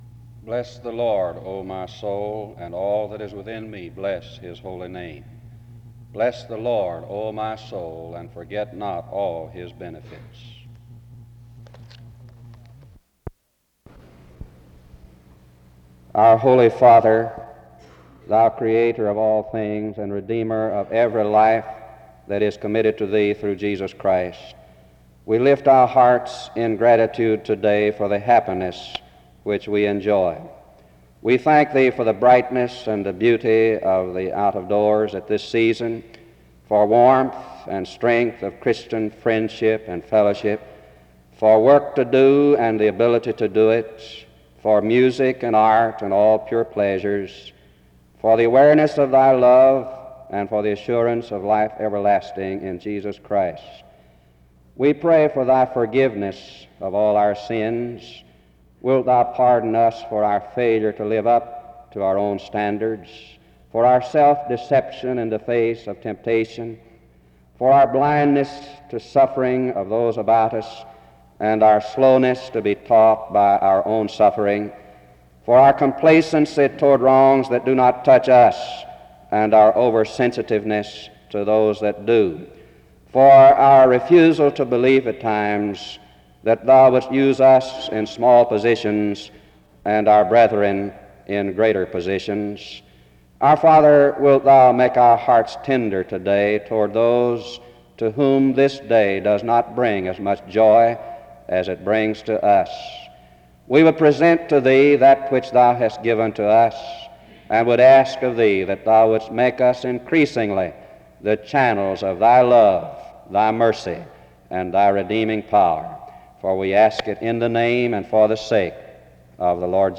SEBTS Chapel